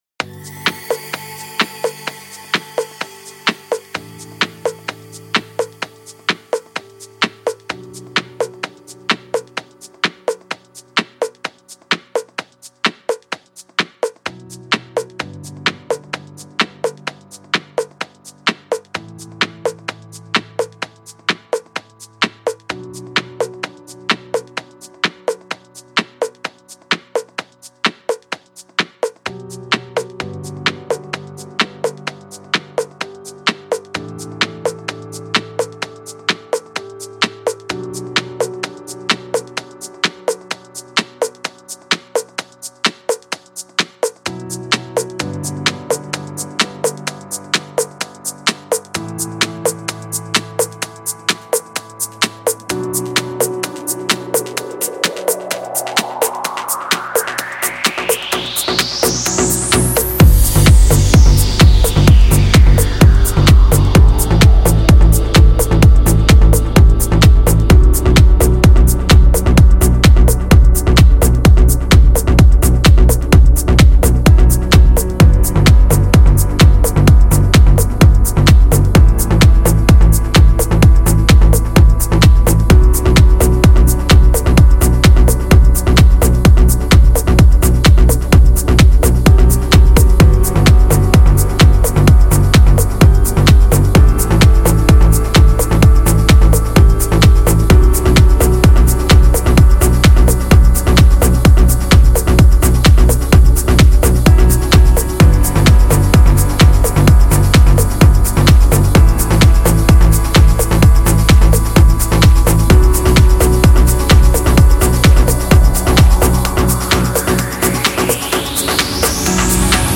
Жанр: Красивая музыка